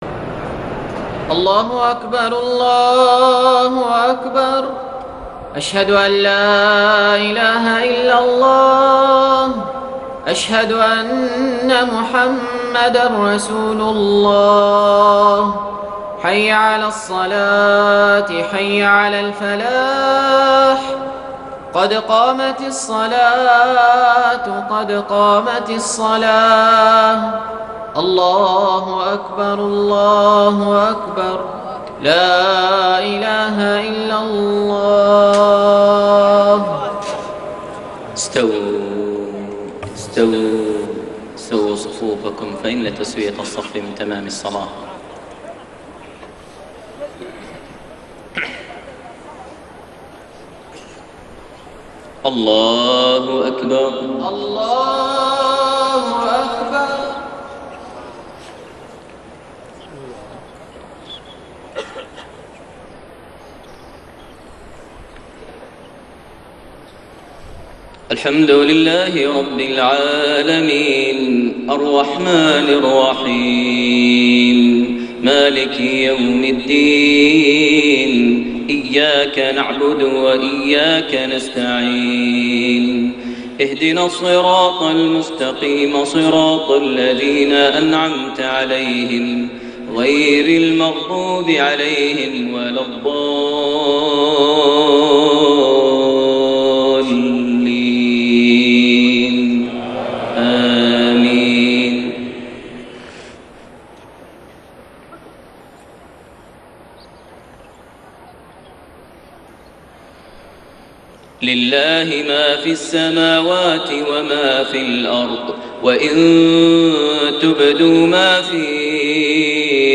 صلاة المغرب 5 شوال 1432هـ خواتيم سورة البقرة 284-286 > 1432 هـ > الفروض - تلاوات ماهر المعيقلي